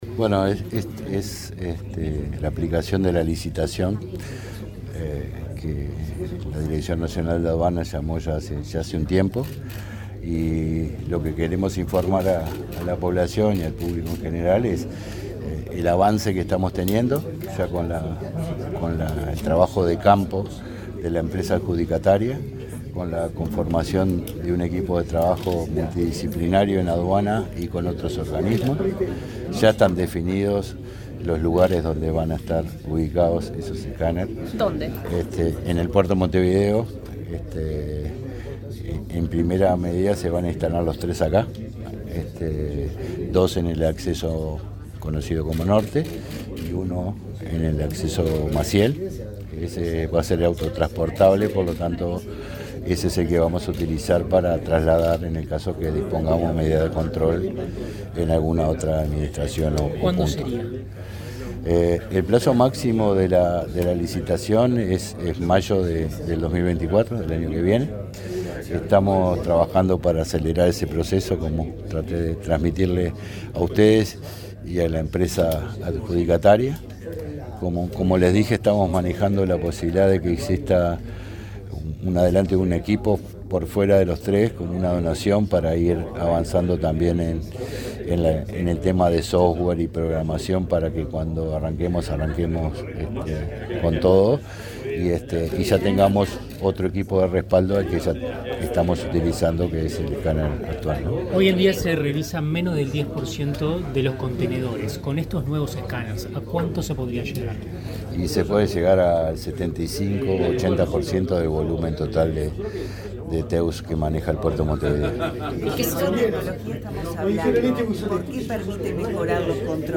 Declaraciones del director nacional de Aduanas, Jaime Borgiani
El director nacional de Aduanas, Jaime Borgiani, dialogó con la prensa, antes de reunirse, en la sede de ese organismo, con autoridades de la